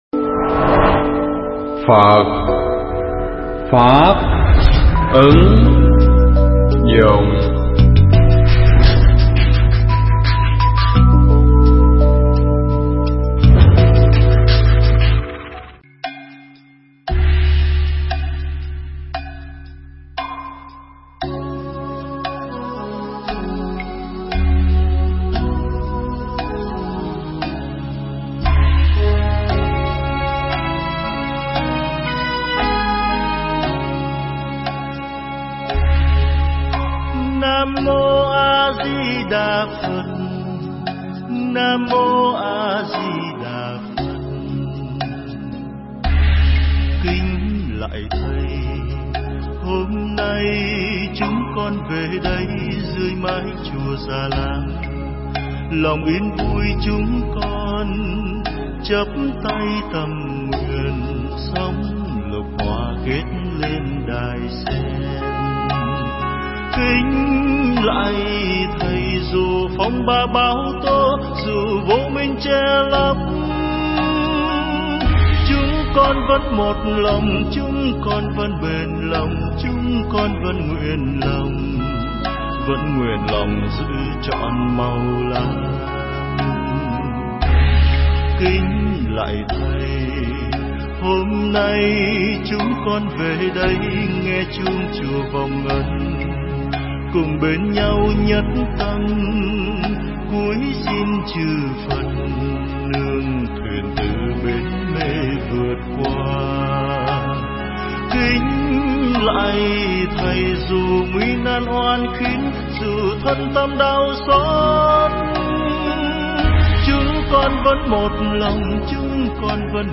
Nghe Mp3 thuyết pháp Ý Nghĩa Ngày Vía Phật A Di Đà
Mp3 pháp thoại Ý Nghĩa Ngày Vía Phật A Di Đà